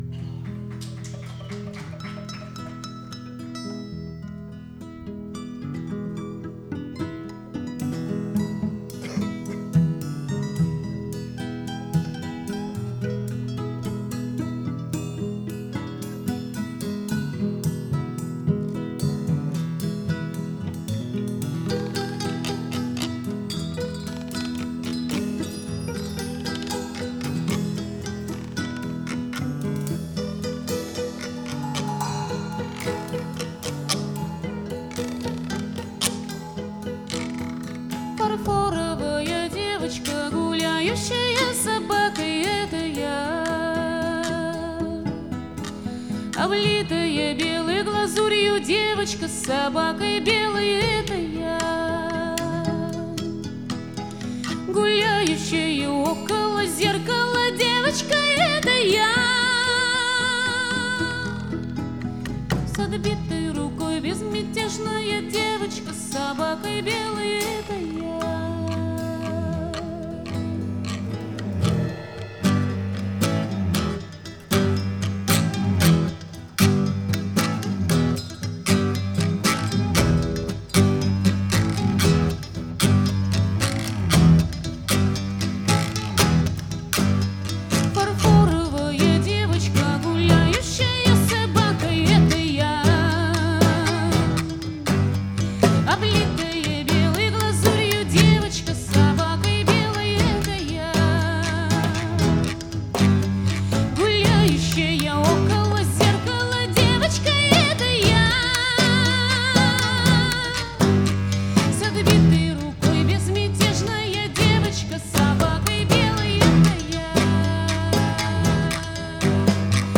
Концертный двойник, стиль — акустика.
голос, гитара
виолончель, голос
флейты, гитары, перкуссия, голос
мандолина, голос